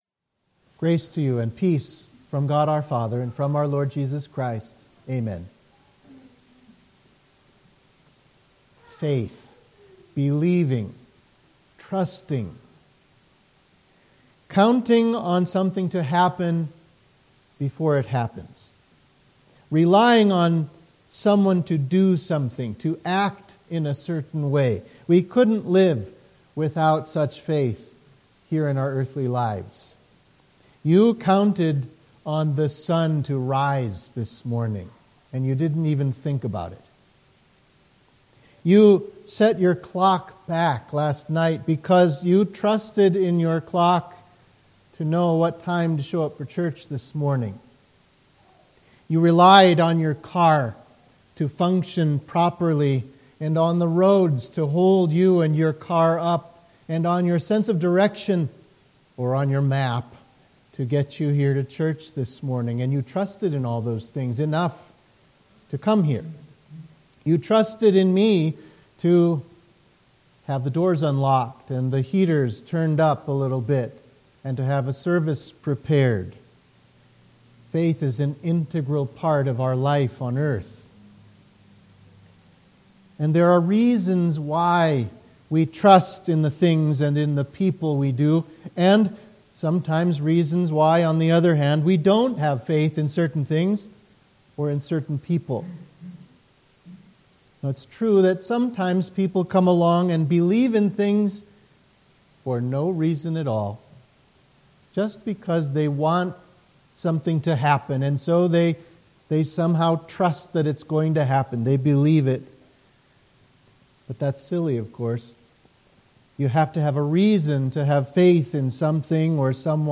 Sermon for Trinity 21